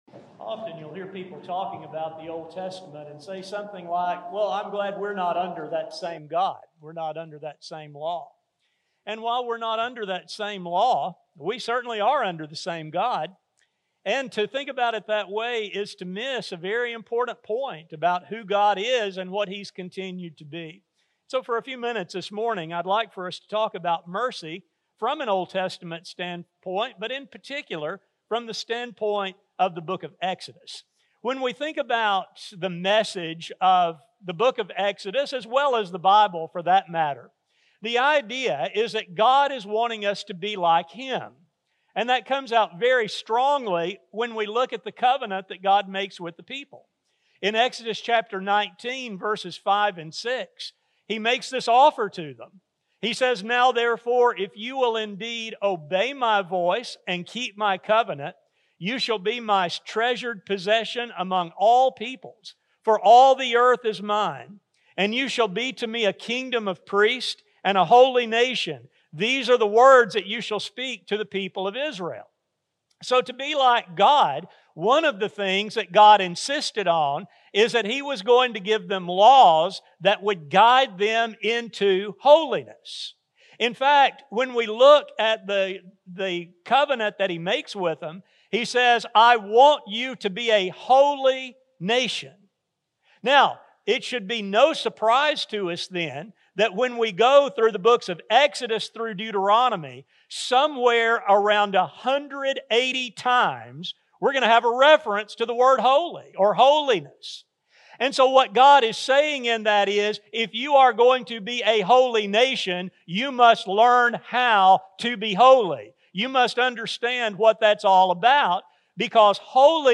This lesson focuses on learning about God’s mercy in the book of Exodus. A sermon recording